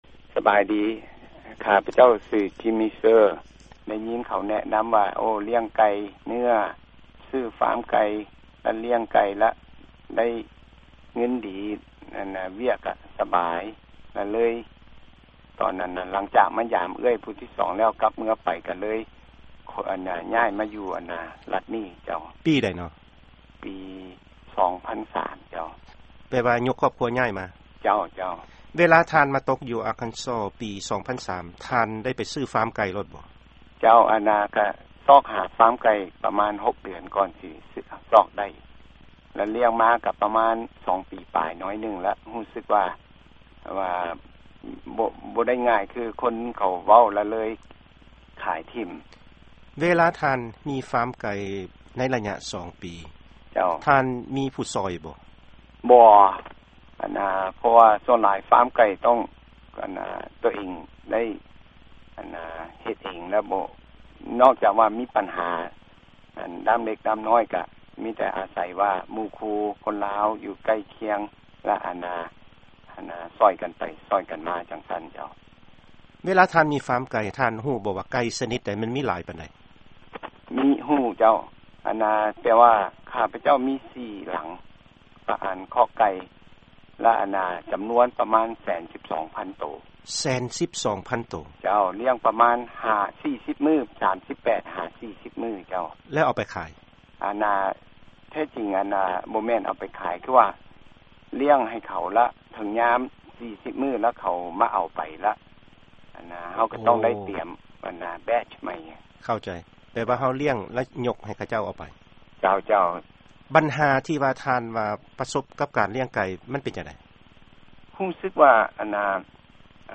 ຟັງຂ່າວການສໍາພາດ